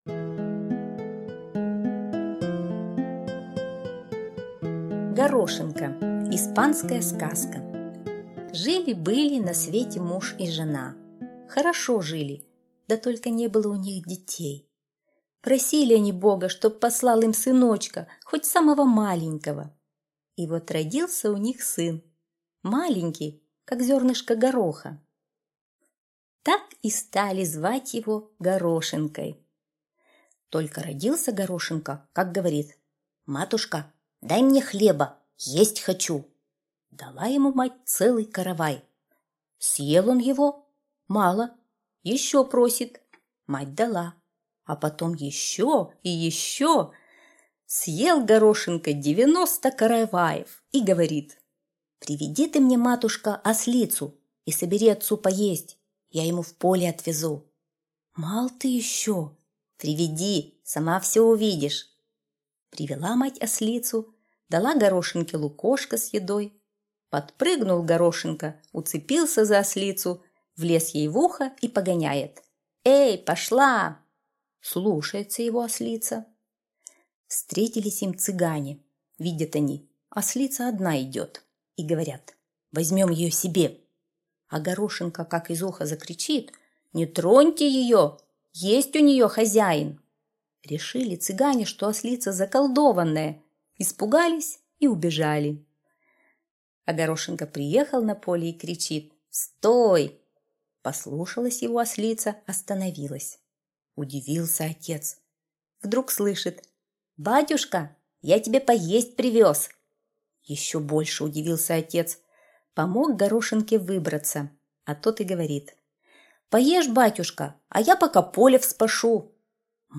Горошинка - испанская аудиосказка - слушать онлайн